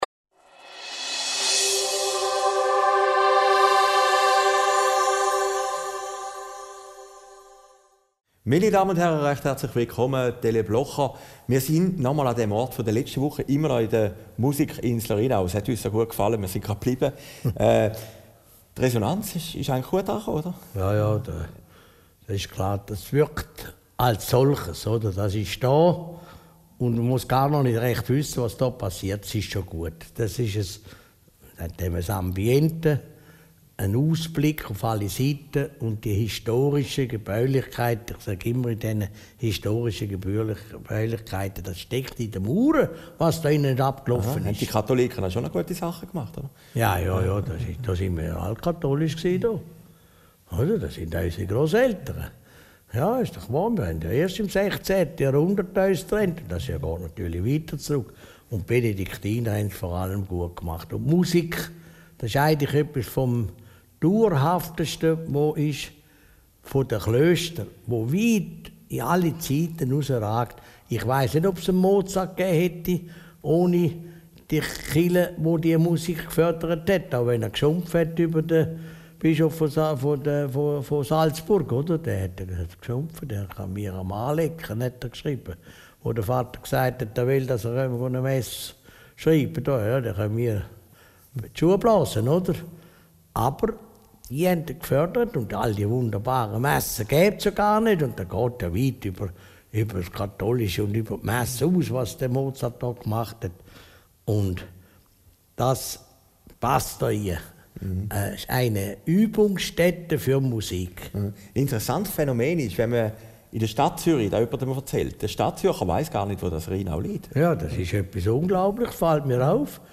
Aufgezeichnet in Rheinau, Ende Mai 2014